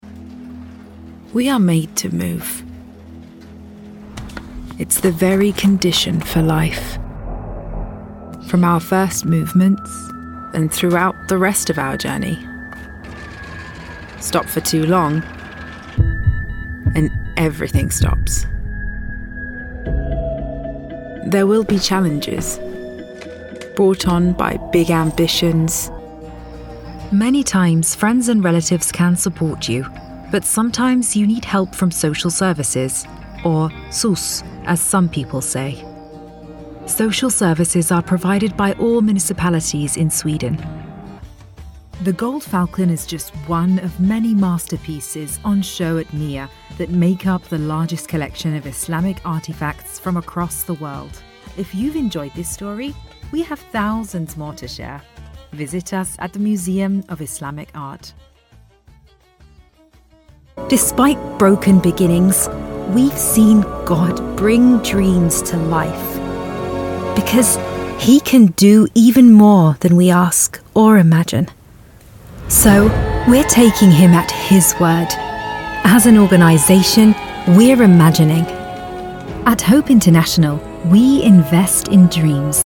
Corporate Videos
Explainer Videos
I have a professional home recording studio and have lent my voice to a wide range of high-profile projects.
LA Booth, Rode, Audient id4
DeepLow
TrustworthyAuthoritativeConfidentFriendlyExperiencedReliable